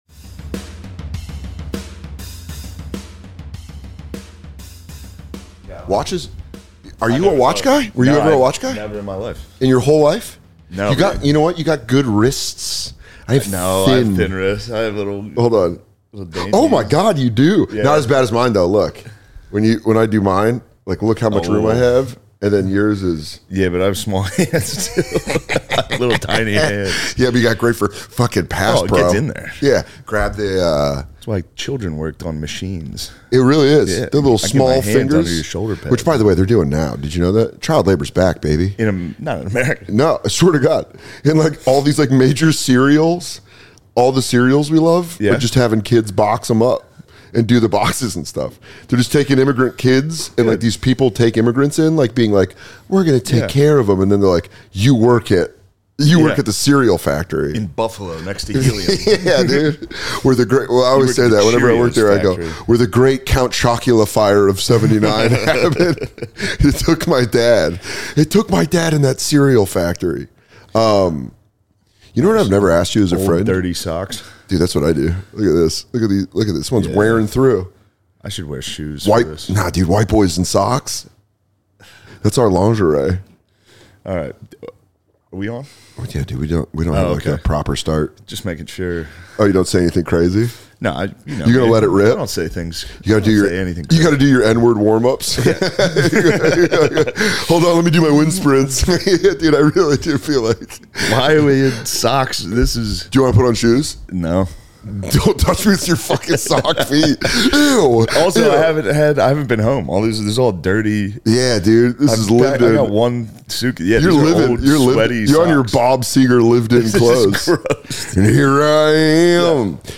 This episode Dan is joined by comedian Shane Gillis! They talk being young in football and the pain of losing.